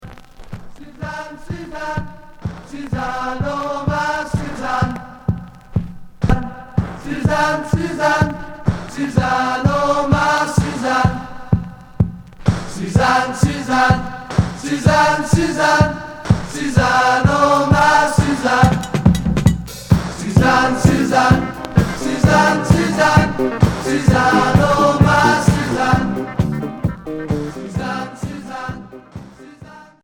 Pop rock Unique 45t